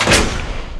fire_mine_tracking.wav